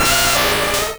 Cri de Rhinoféros dans Pokémon Rouge et Bleu.